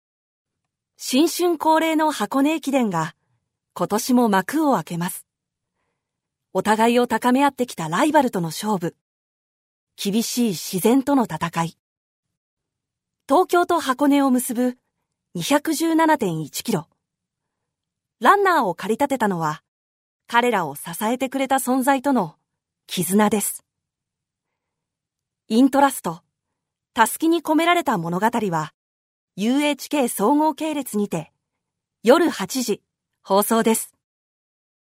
Voice Sample
ナレーション2